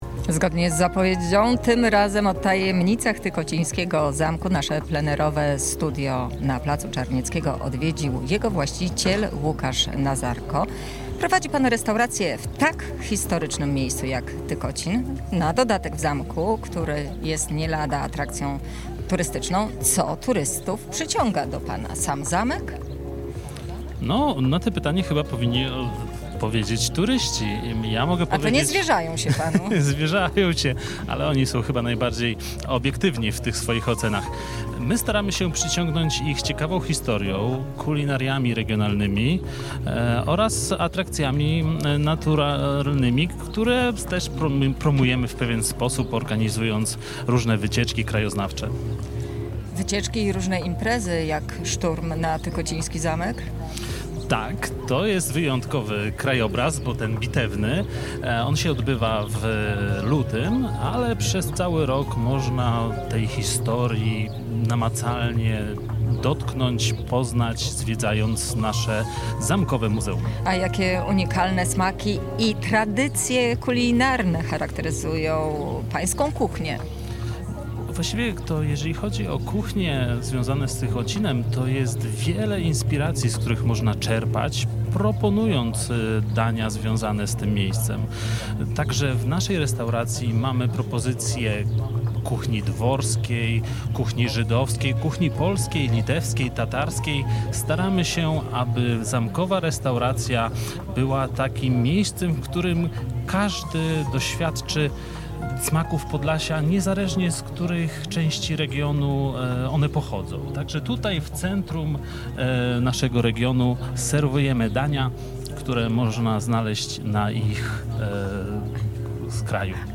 Nasz program z Tykocina nadawaliśmy od 14:00 do 18.00. W naszym plenerowym studiu na Placu Czarnieckiego nasi słuchacze mogli podpatrzeć pracę dziennikarzy, ale także wziąć udział w wielu konkursach, które przygotowaliśmy.